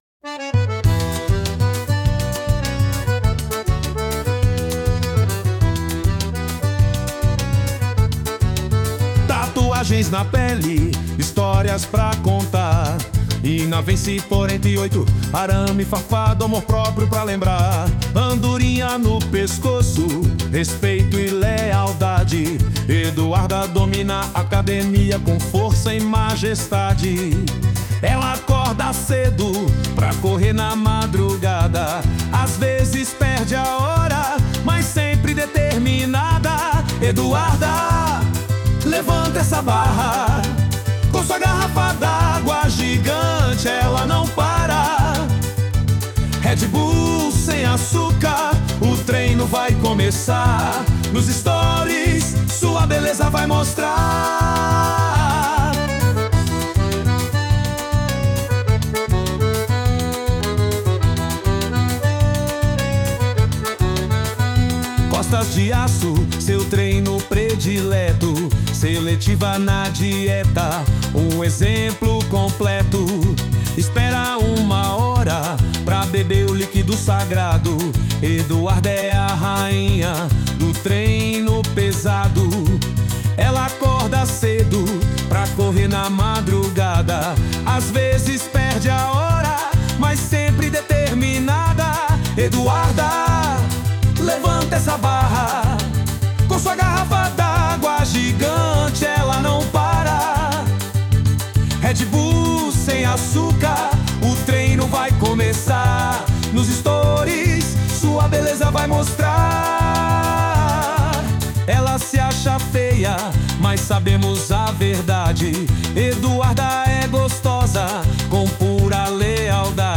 Versão Forró 2